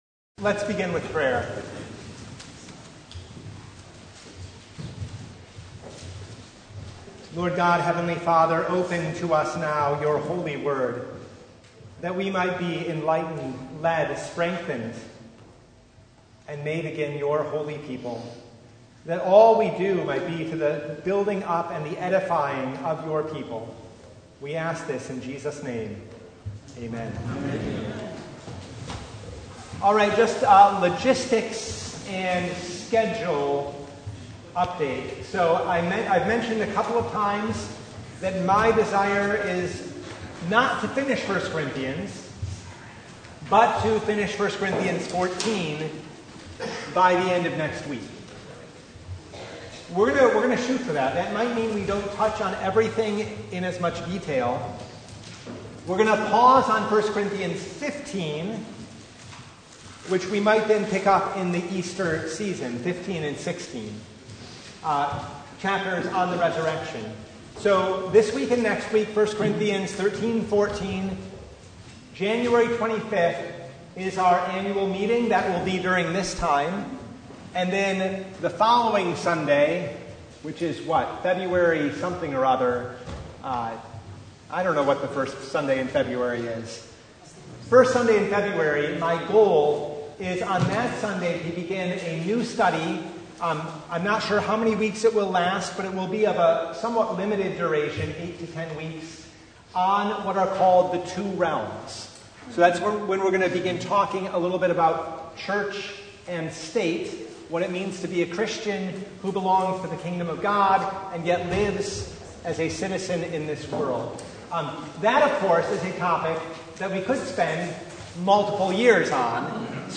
1 Corinthians 13:8-14:5 Service Type: Bible Hour Topics: Bible Study